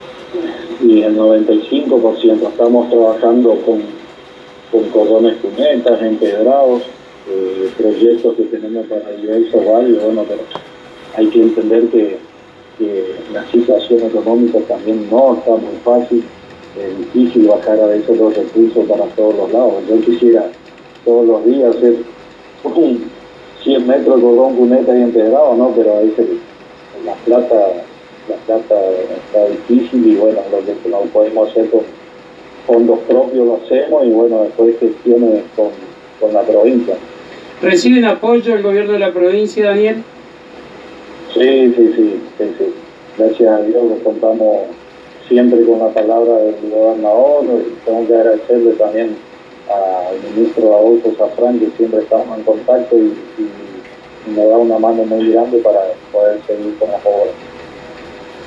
El Intendente de Azara Daniel Yendrika, en diálogo con la ANG y FM Éxito de Apóstoles, informó sobre los trabajos realizados en el Balneario Municipal que está a la vera del Arroyo Chimiray en el límite con la Provincia de Corrientes. También destacó la importancia e influencia que tendrá la ruta Provincial 2 en el contexto Provincial, Nacional e Internacional.